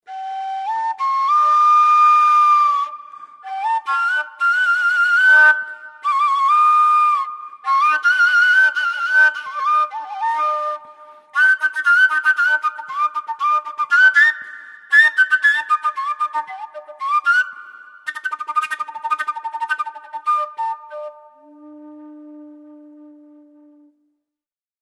Flauta Bastón
A partir de ahí, el intérprete sólo cuenta con el orificio final del tubo y con la presión del propio aire para obtener las diferentes notas. Generalmente se obtienen armónicos naturales, que se alteran en intervalo de un tono al tapar el orificio final. El resultado acústico es de una enorme riqueza tímbrica, ya que con frecuencia surgen numerosos armónicos paralelos durante la ejecución.
Tema: Improvisación
flautabaston.mp3